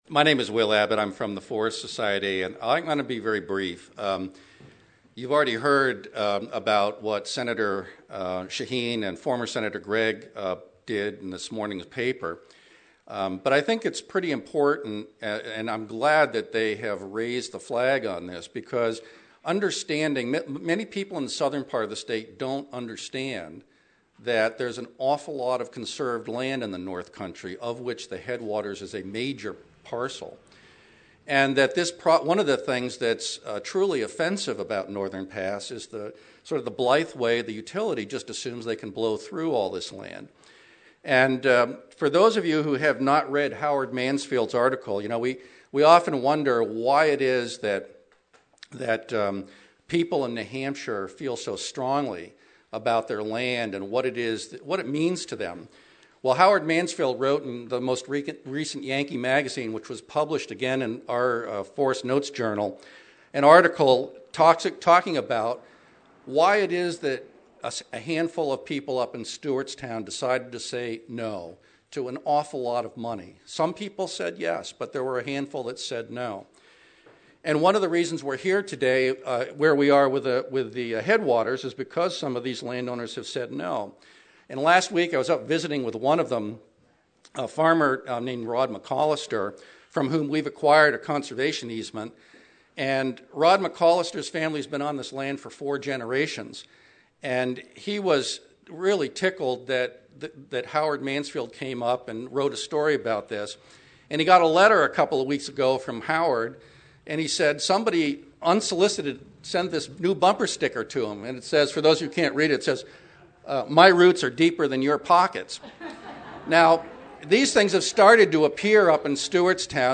About 135 people attended a meeting of concerned citizens at the Easton Town Hall for a discussion on a broad range of topics relating to Northern Pass about the Connecticut Headwaters easement, alternatives to Northern Pass, costs of burying the line and potential revenue to the state.